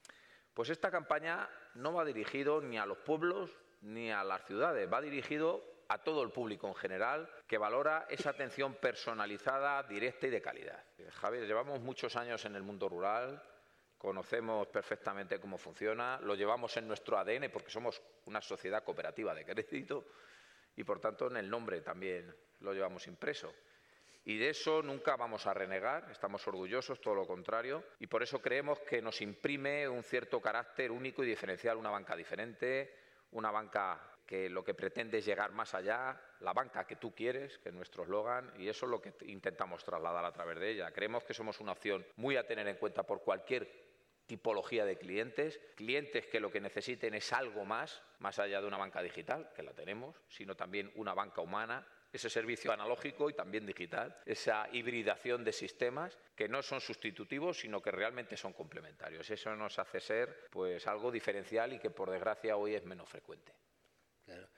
Los distintos spots han sido rodados en una oficina real de Eurocaja Rural.